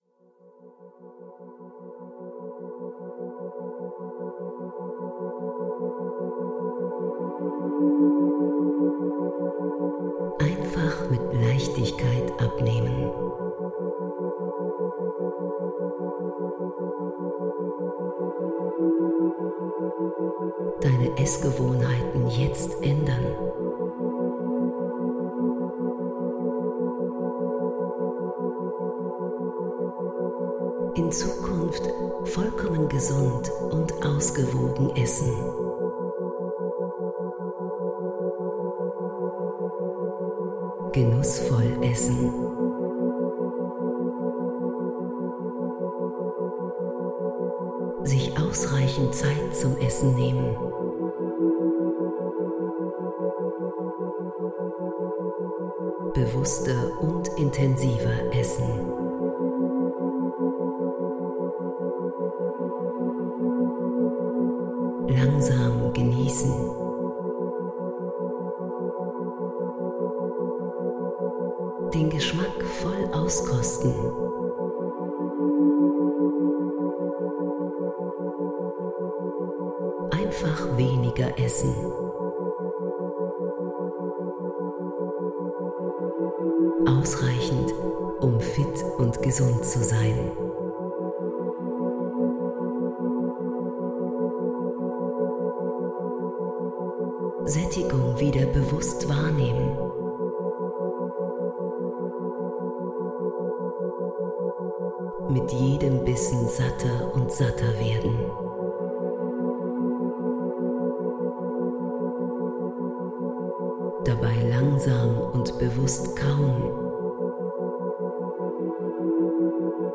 Mentaltraining zur Gewichtsreduktion – Denken Sie sich schlank!
Das Besondere an diesem Audiotraining ist die spezielle neurologische Hintergrundmusik Neuroflow. Diese stimuliert die Gehirnfrequenz so, dass die positiven Suggestionen optimal wirken und Sie Ihre unbewussten Potentiale nutzen können.